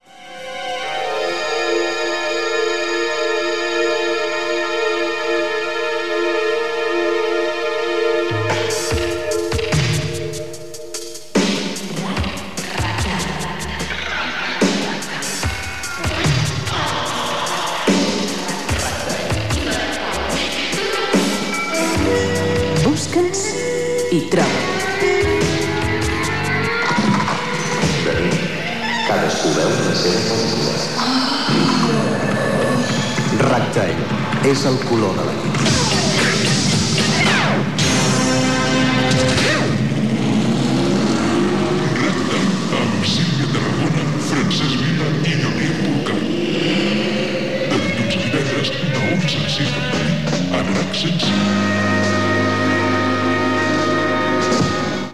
Separador del programa amb identificació i noms de l'equip.
Musical
FM